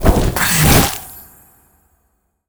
spell_harness_magic_04.wav